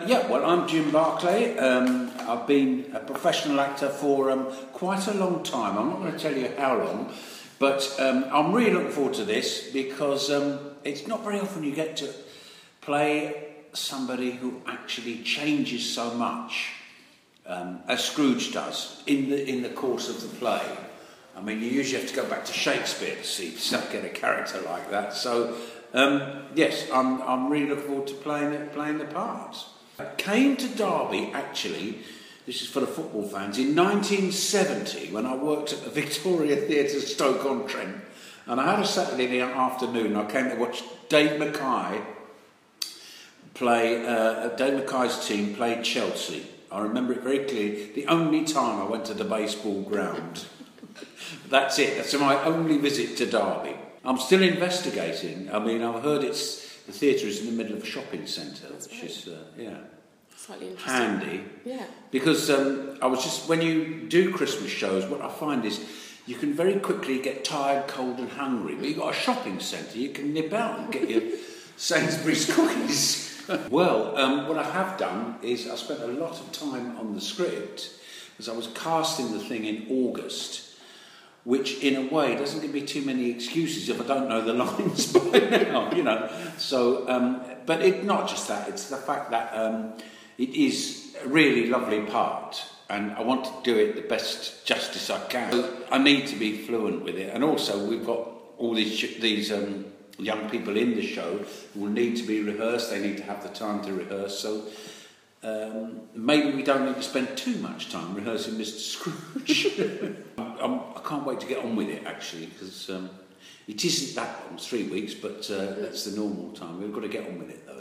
A Christmas Carol - Interview